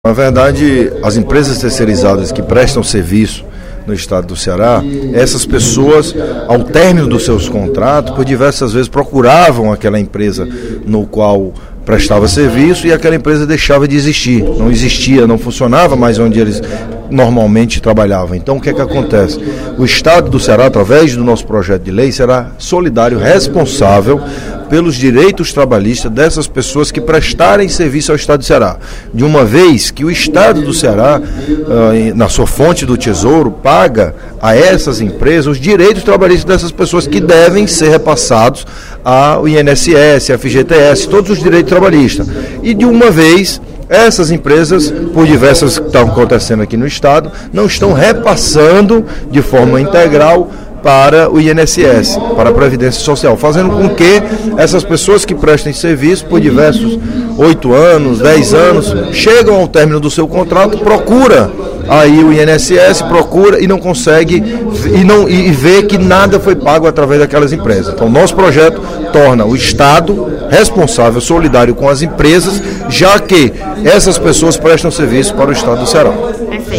O deputado Danniel Oliveira (PMDB) destacou, no primeiro expediente da sessão plenária da Assembleia Legislativa desta sexta-feira (26/06), o projeto de lei nº 97/15, de sua autoria, que torna obrigatória, para pagamento de serviços terceirizados contratados pelo Estado, a apresentação de documentos comprobatórios de recolhimento mensal previdenciário e obrigações trabalhistas.